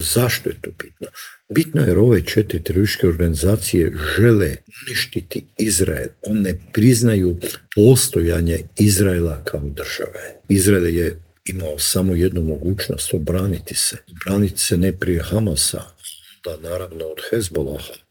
Cilj je bio eliminirati predsjednika Franju Tuđmana, a tog se dana u Intervjuu tjedna Media servisa prisjetio bivši ministar vanjskih poslova Mate Granić.